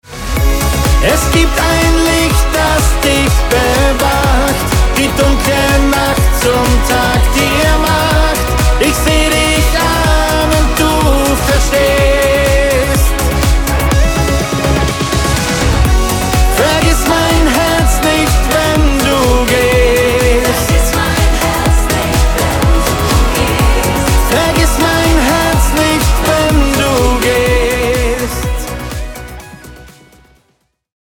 emotionaler neuer Single
ruhigere und nachdenklichere Seite